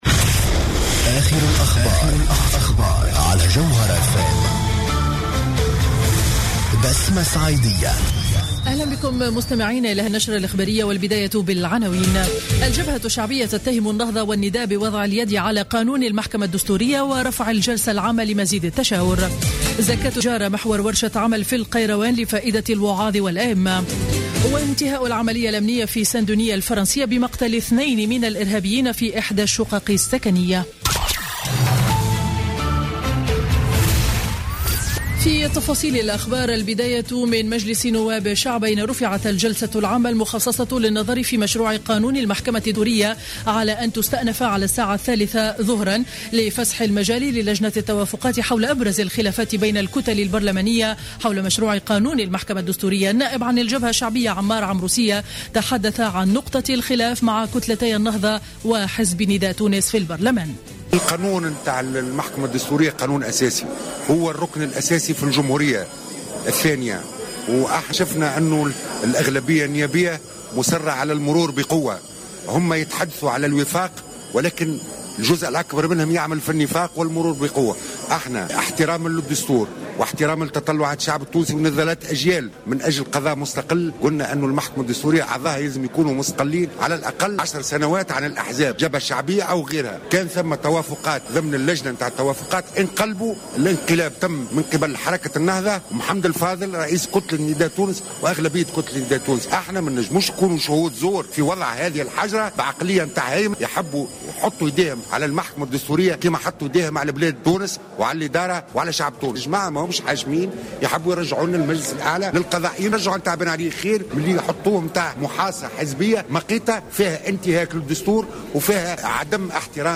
نشرة أخبار منتصف النهار ليوم الإربعاء 18 نوفمبر 2015